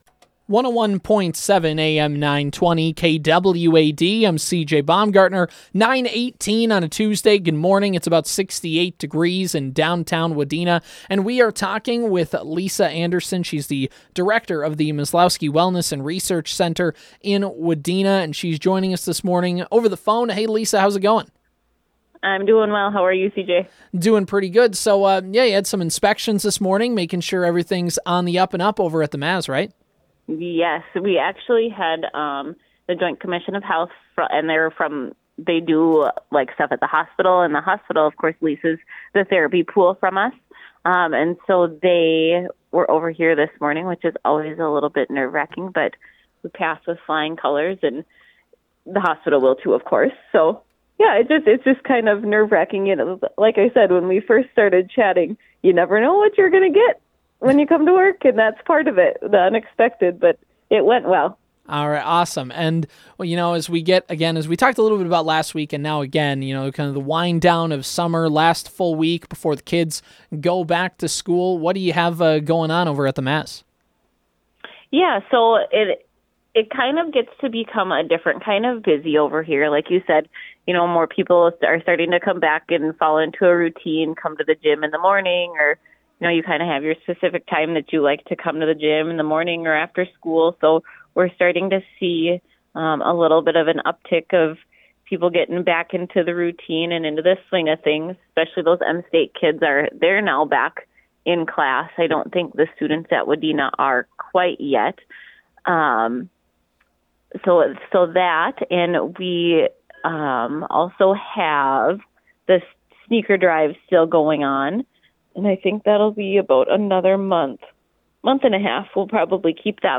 Maslowski Wellness And Research Center Interview: 8/29/23 – Superstation K-106